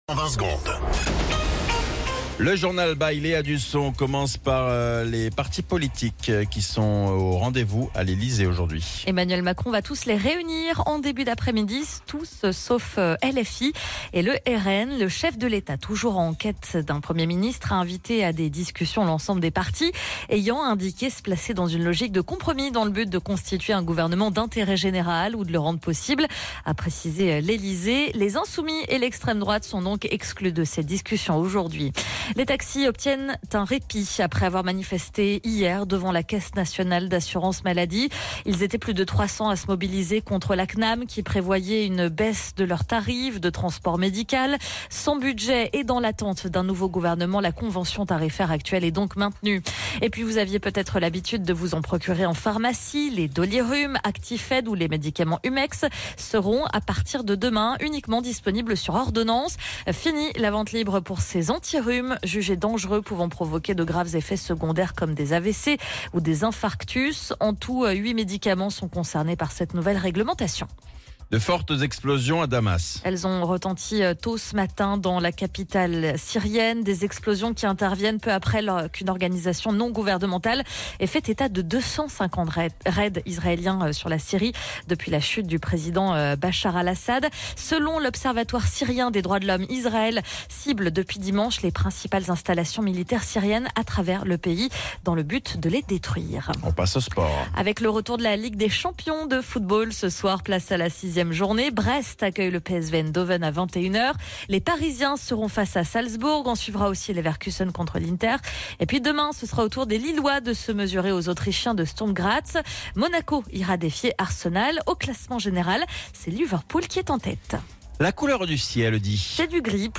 Flash Info National 10 Décembre 2024 Du 10/12/2024 à 07h10 .